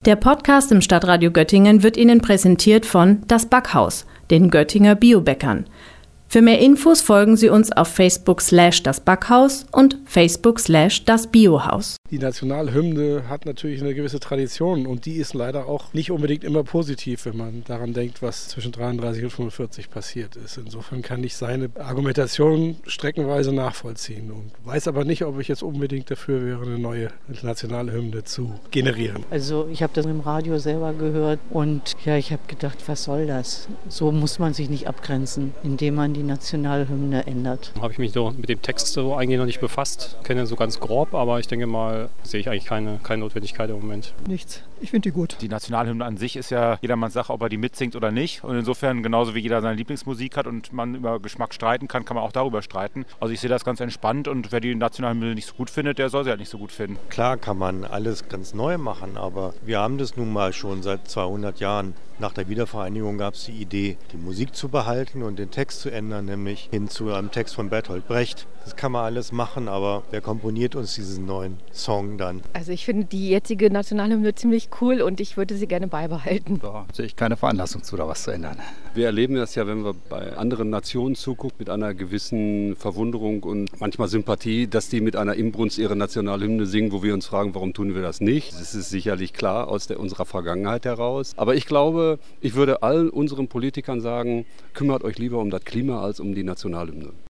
in der Göttinger Innenstadt erfragt.